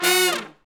Index of /90_sSampleCDs/Roland LCDP06 Brass Sections/BRS_R&R Horns/BRS_R&R Falls